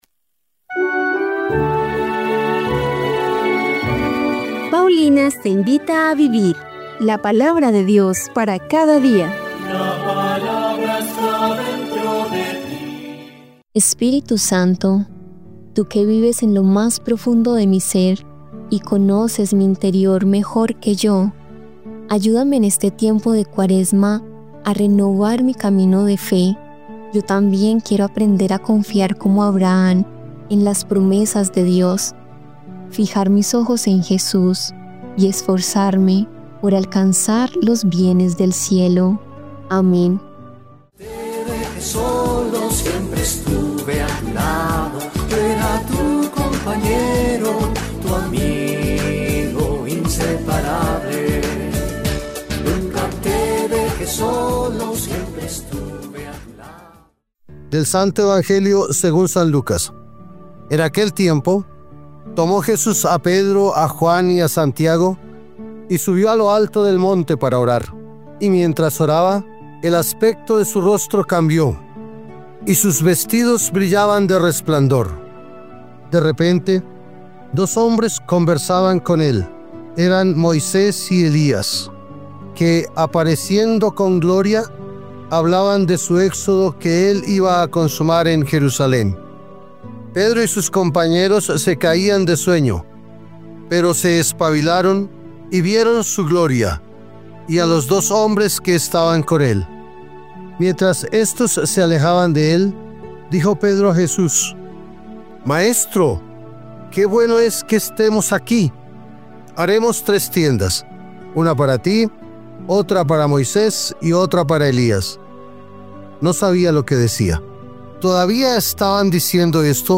Lectura del libro de Jeremías 11, 18-20